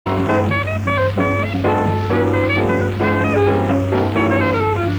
Billy Berg's Club, Hollywood CA